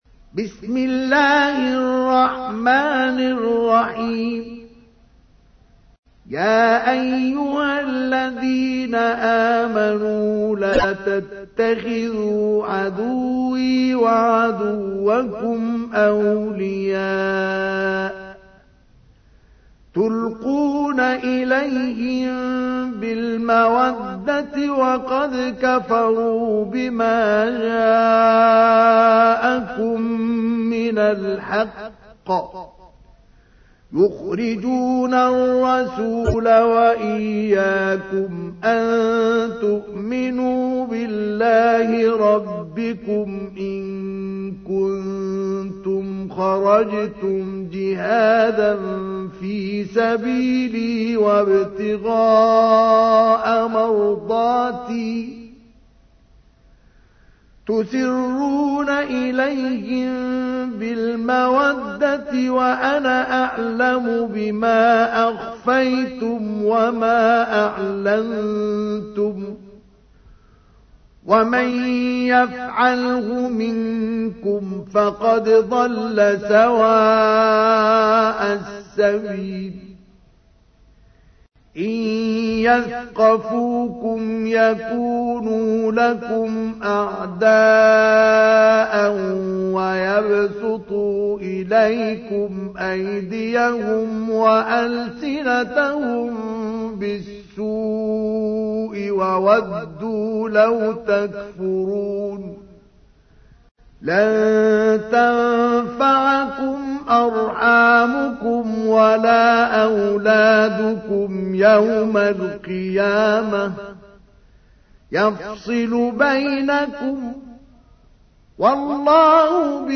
تحميل : 60. سورة الممتحنة / القارئ مصطفى اسماعيل / القرآن الكريم / موقع يا حسين